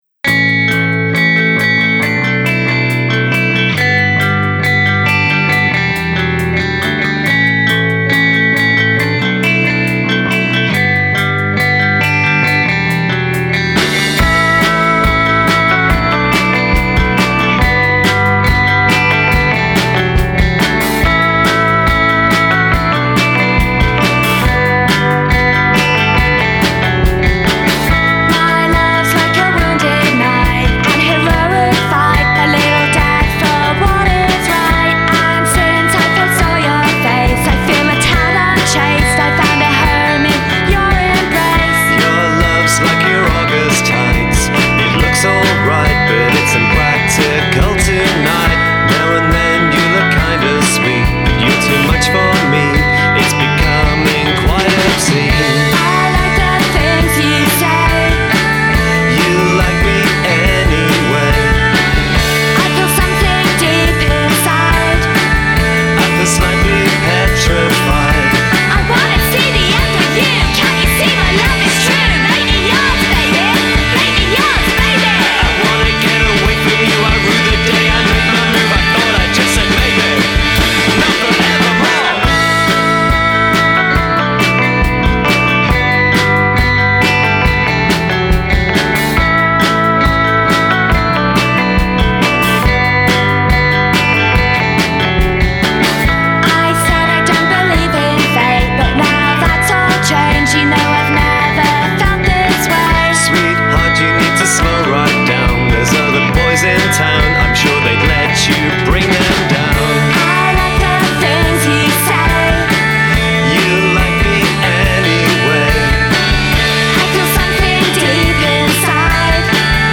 A bright poppy indie band from Brighton England